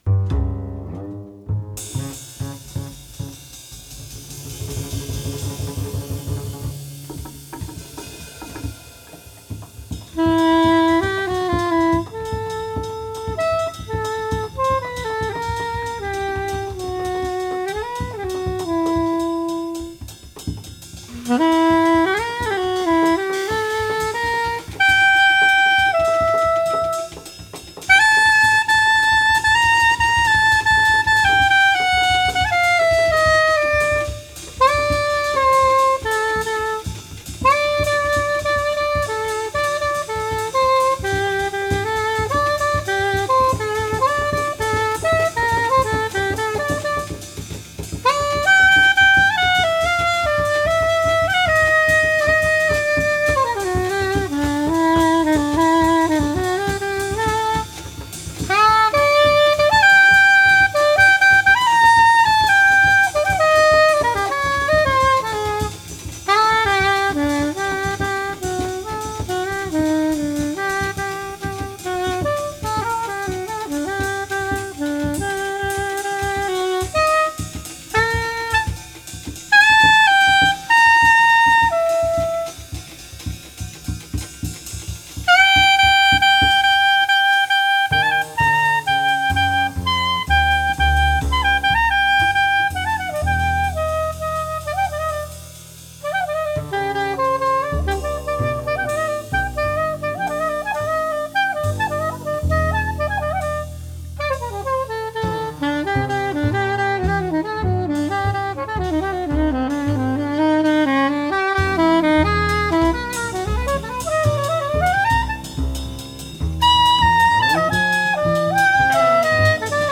Recorded live
bass
drums
baritone saxophone
tenor saxophone
Stereo (Tascam portable / Pro Tools)